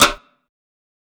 TC2 Snare 22.wav